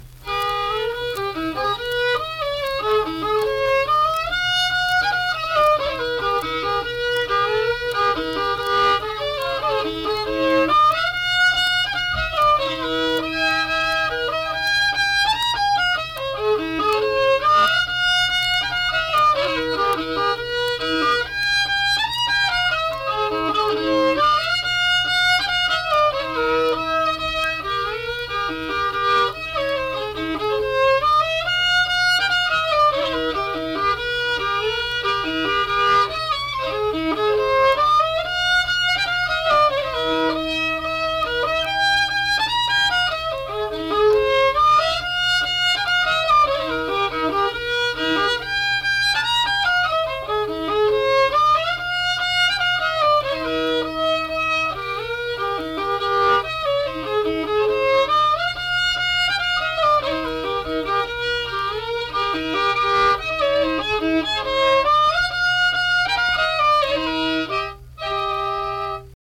Schottische
Unaccompanied fiddle music and accompanied (guitar) vocal music performance
Instrumental Music
Fiddle
Braxton County (W. Va.)